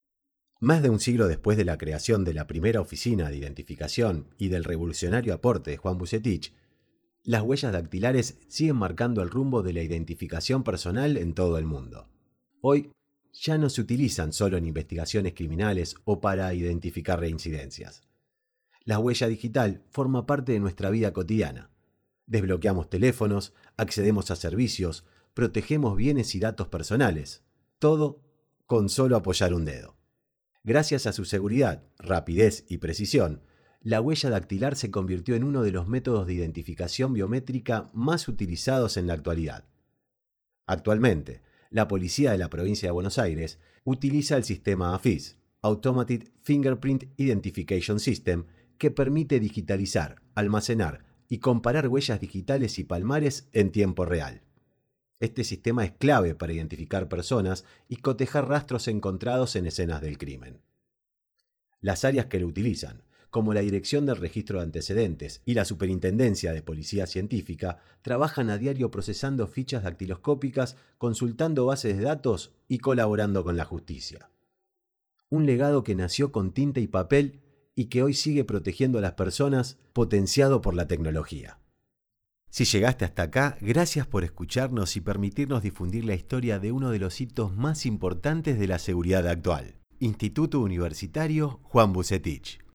Audiolibros